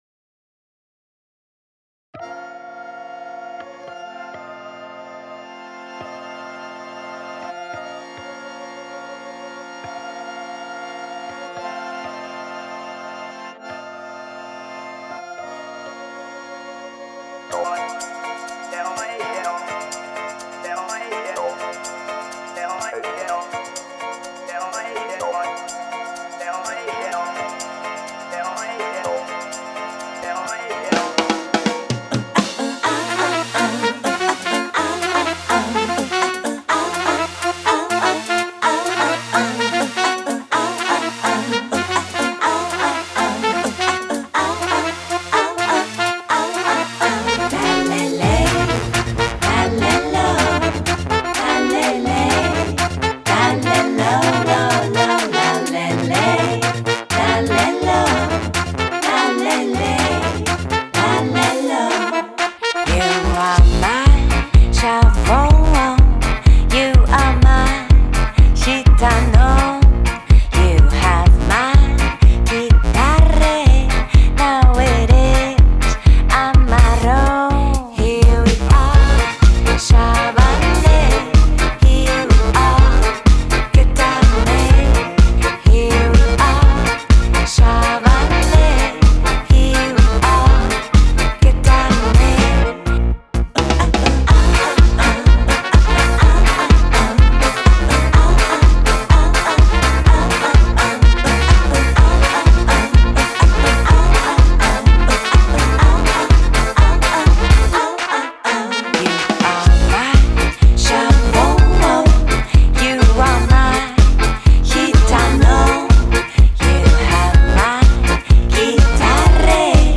Genre: Electronic, Gypsy Jazz, Balkan, Folk, World